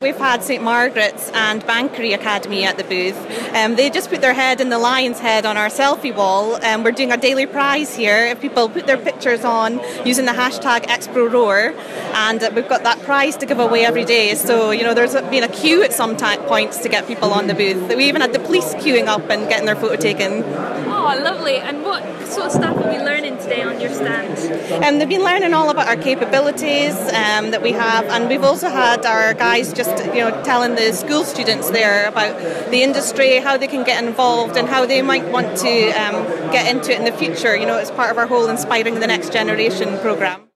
Northsound 1 is providing a flavour of Offshore Europe 2017.